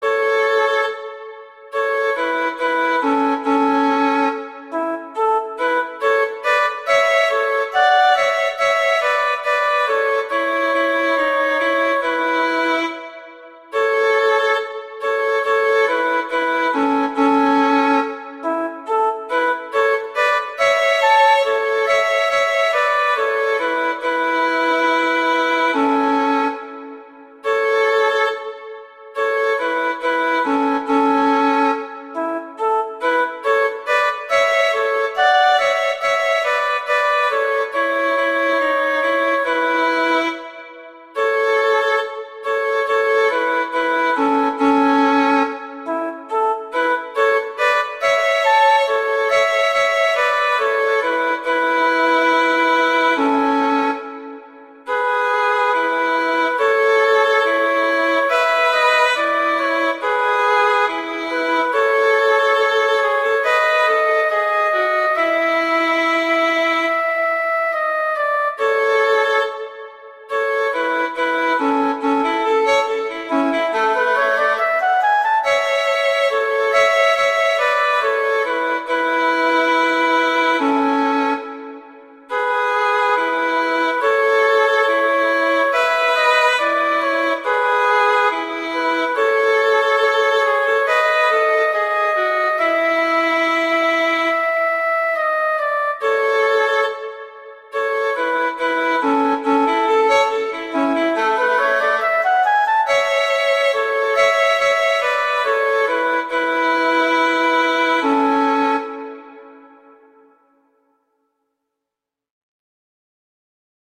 classical, children
D major, A major, G major, Bb major
♩=70-120 BPM (real metronome 69-120 BPM)